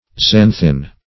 Xanthin \Xan"thin\, n. [Gr. xanqo`s yellow.]